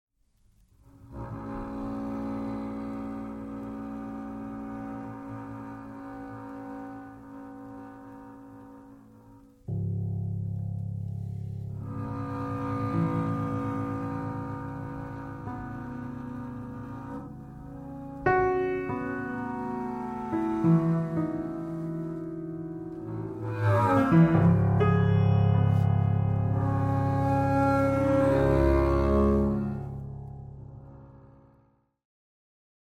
at Secret House Studio, Amsterdam
contrabajo
piano preparado